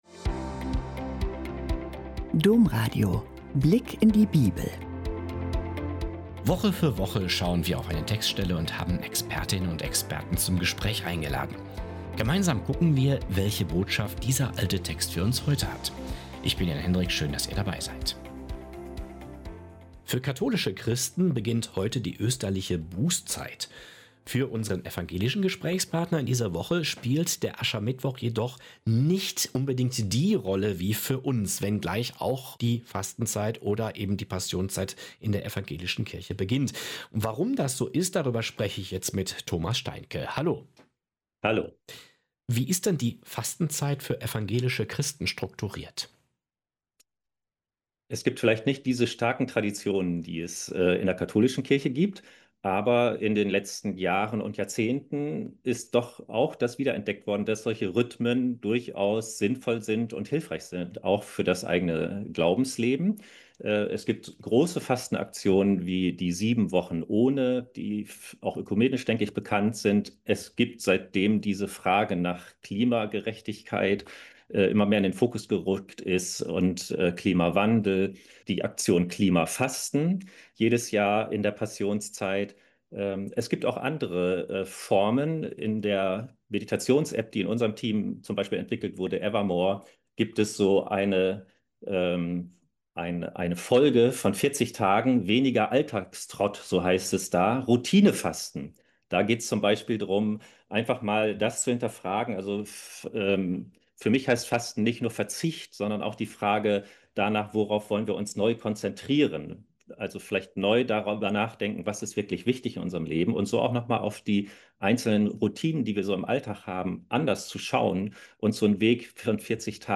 "Glauben ohne Berechnung" - Gespräch